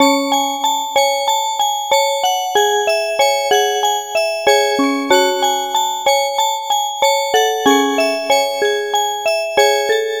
Ridin_ Dubs - Music Box.wav